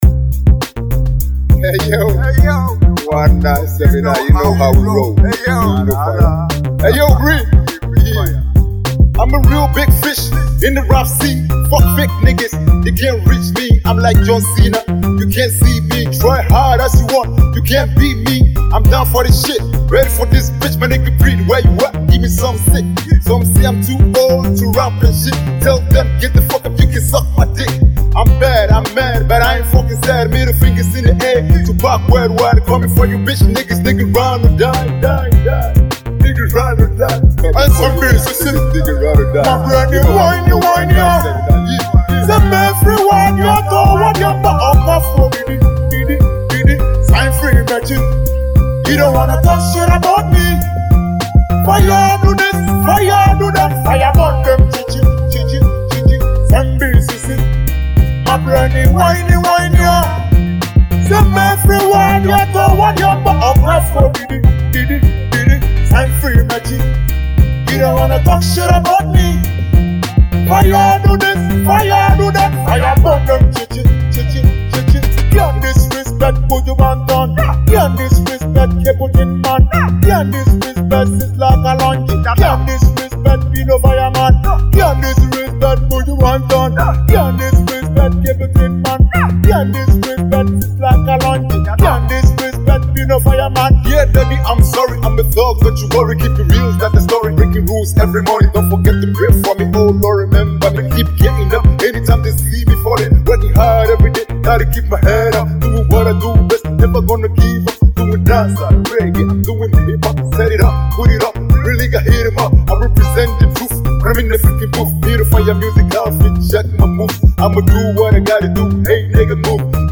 instrumental with both a hip hop and Jamaican style